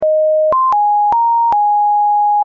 The beep boops heard around 5:40 in Petscop 6
Petscop6-Tones.ogg.mp3